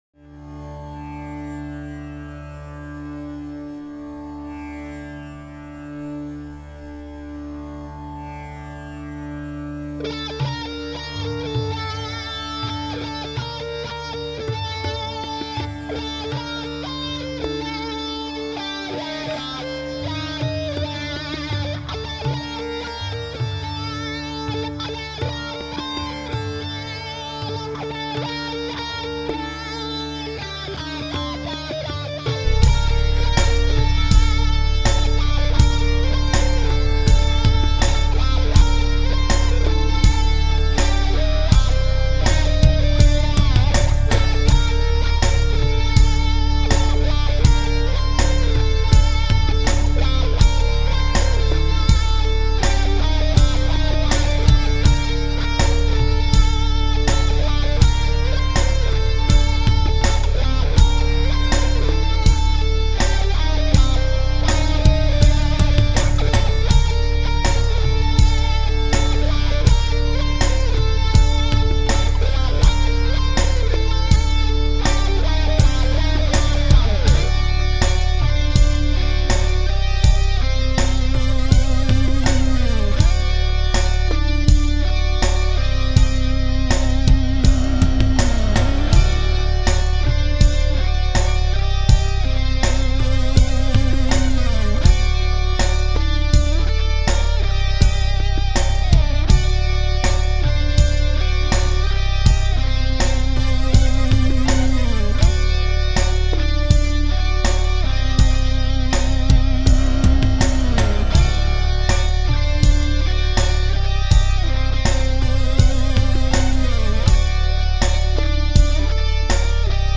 It has an Indian vibe to it.
I remixed and re-recorded this tune